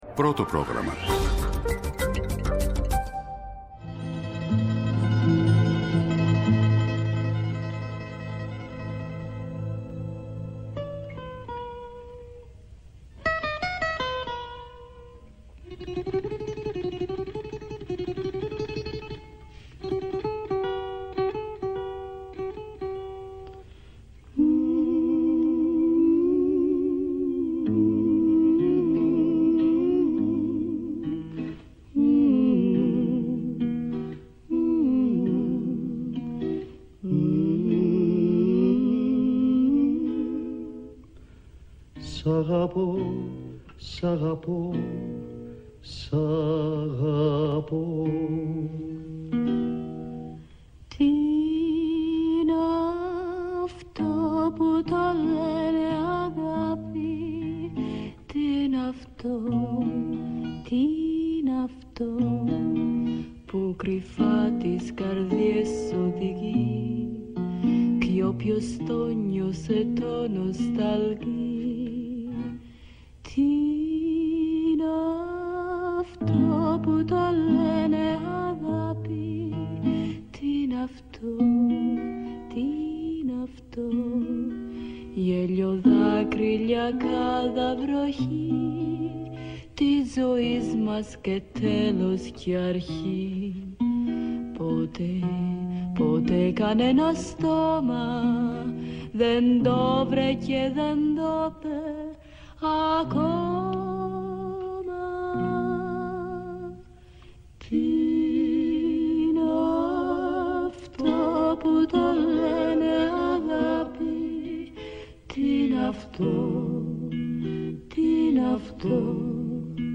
ακούμε τα σάουντρακς